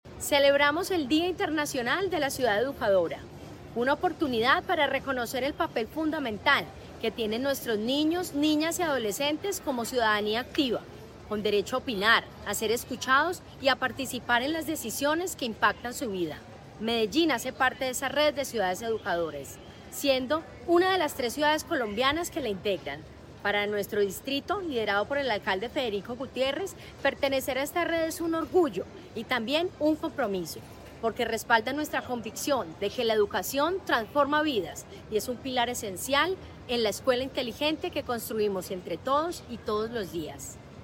Declaraciones de la secretaria de Educación, Carolina Franco Giraldo
Declaraciones-de-la-secretaria-de-Educacion-Carolina-Franco-Giraldo-3.mp3